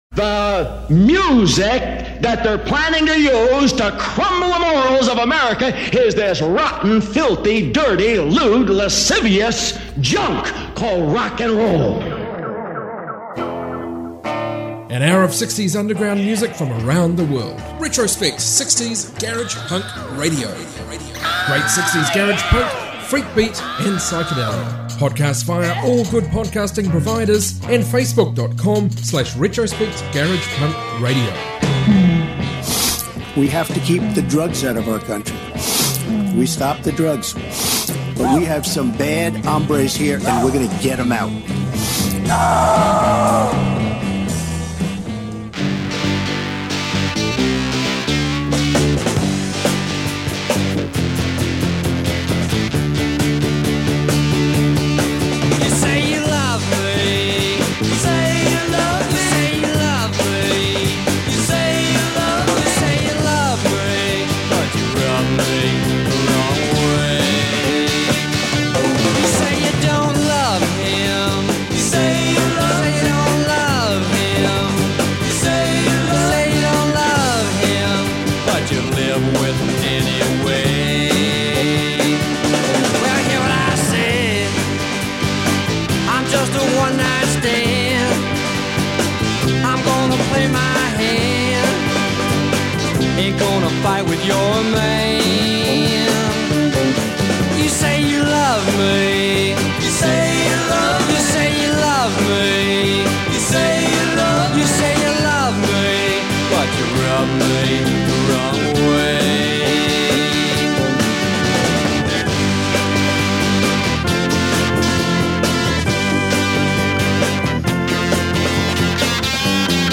global 60s garage rock